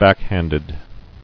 [back·hand·ed]